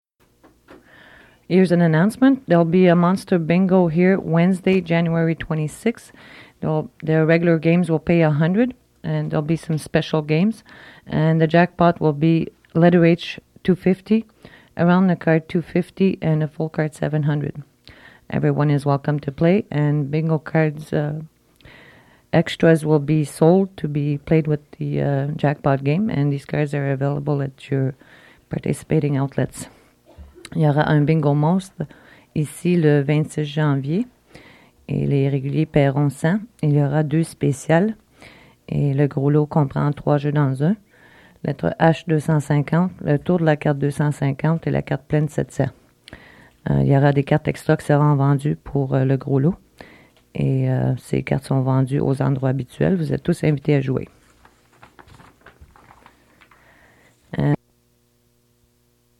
Fait partie de Bingo announcement